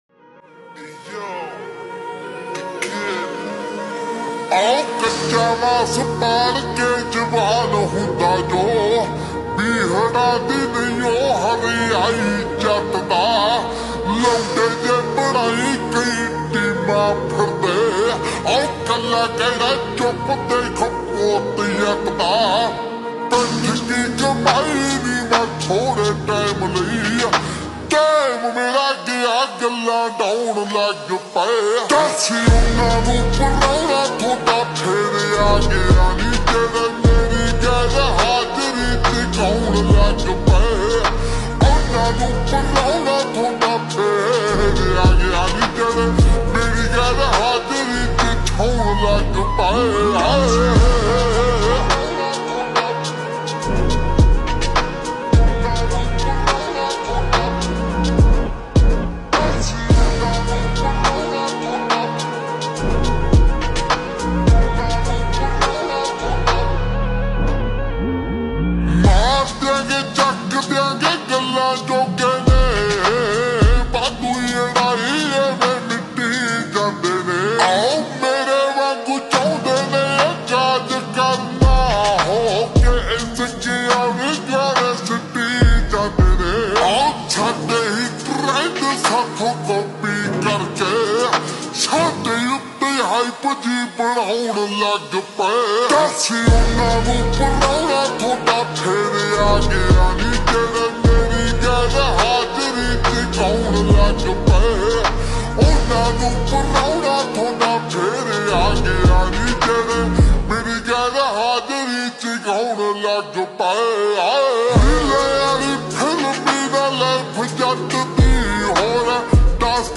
Slowed and reverbed